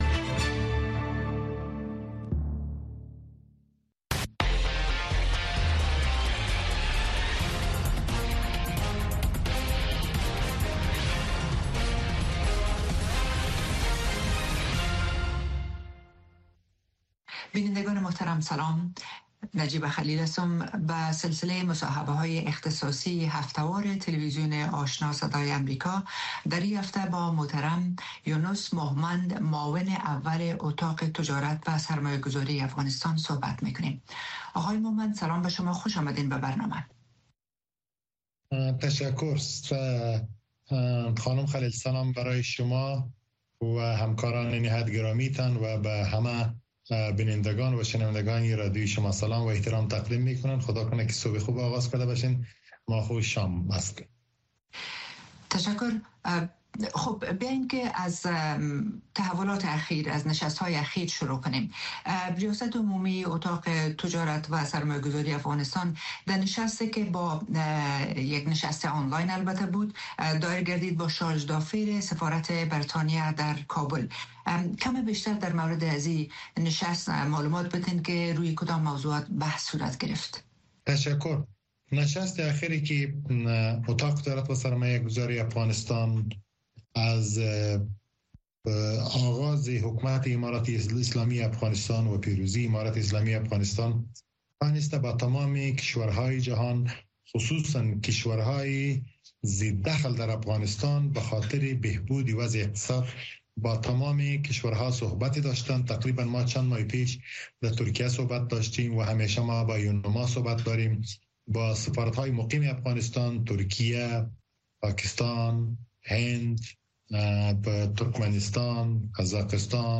گفتگوی ویژه